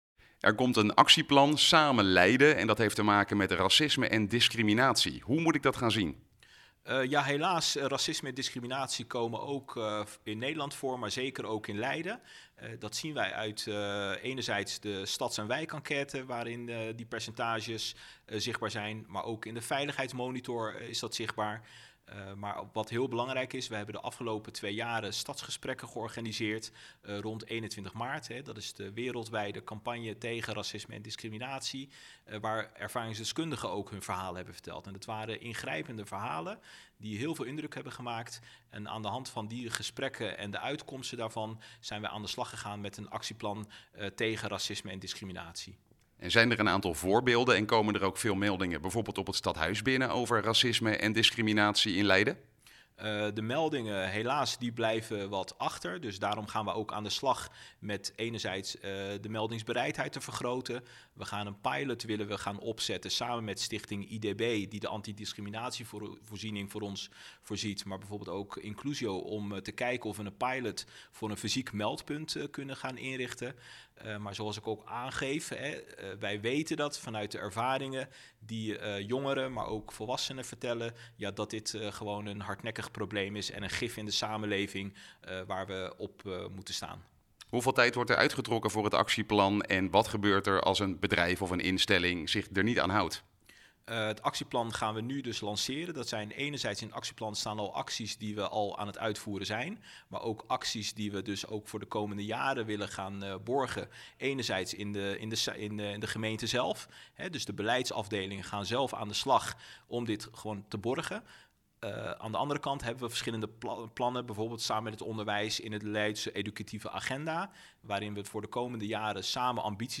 Verslaggever
wethouder Abdelhaq Jermoumi